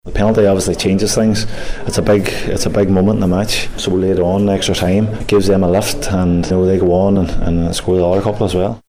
Here's defender